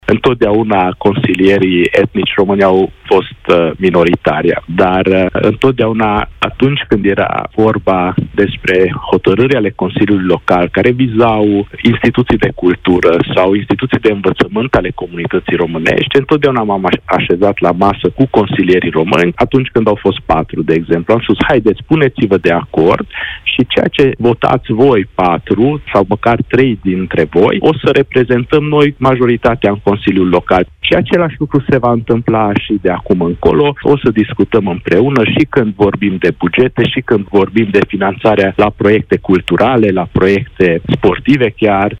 Antal Arpad, primarul orașului Sfântu Gheorghe: „Întotdeauna m-am așezat la masă cu consilierii români”